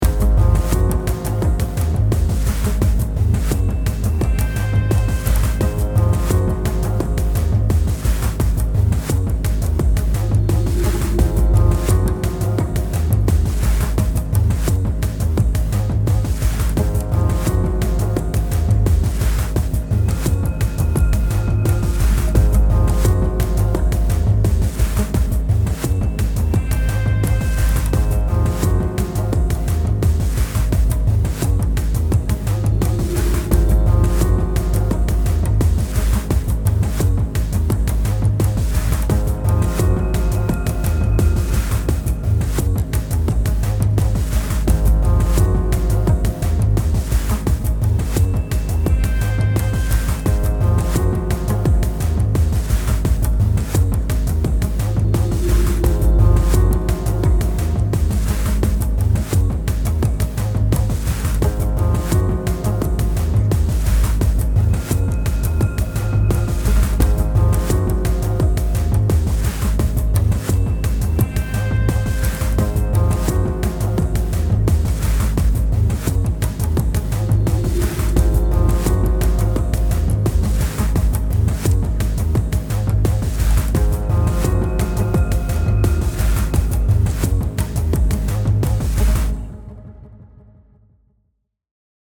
リズムが加わりカッコ良い雰囲気に変わっています。
踊り ノリノリ 楽しい ワクワク ダンス かっこいい パーカッション リズム 単純